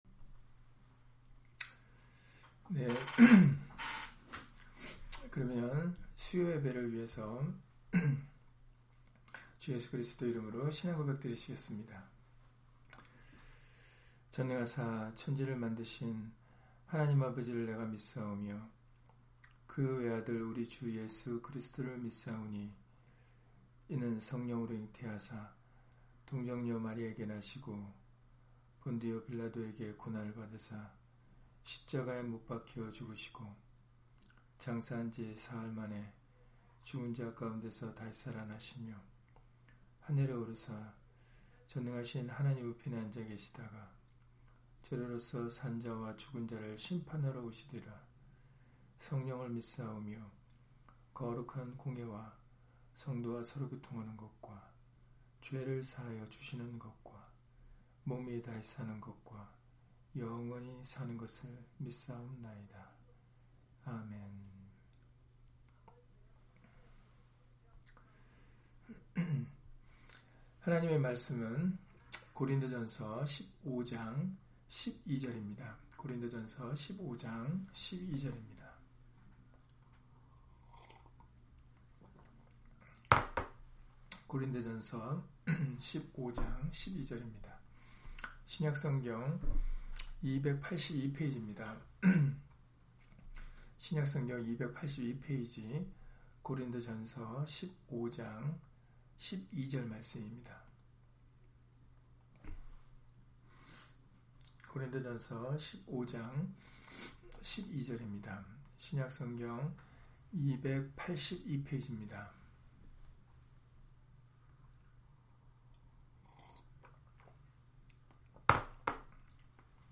고린도전서 15장 12절 [어찌하여 부활이 없다 하느냐] - 주일/수요예배 설교 - 주 예수 그리스도 이름 예배당